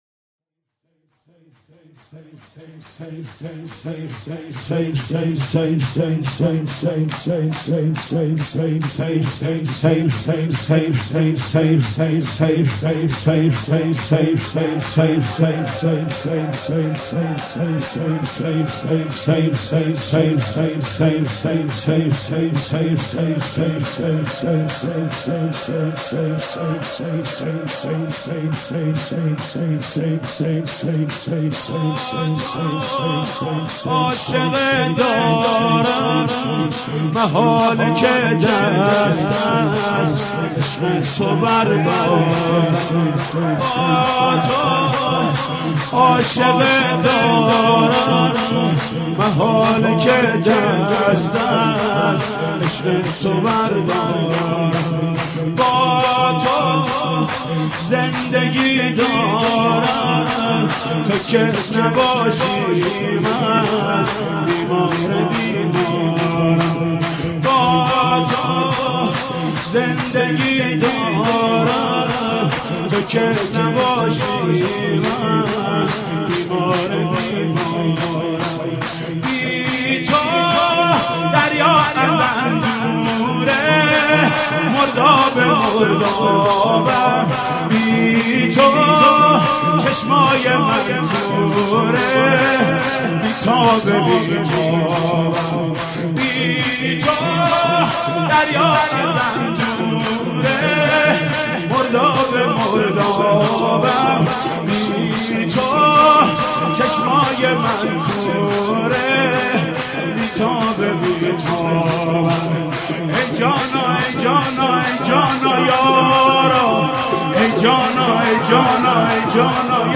شب هشتم (شور)